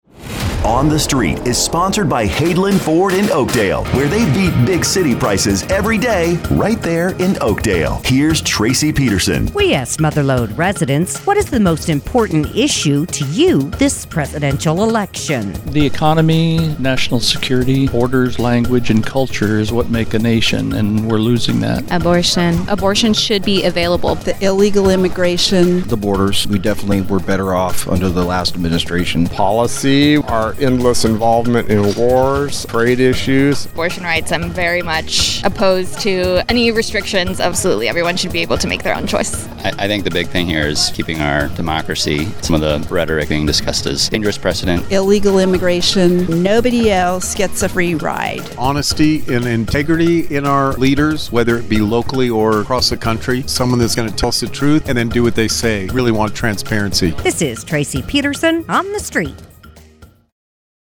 asks Mother Lode residents, “What is the most important issue to you this Presidential Election?”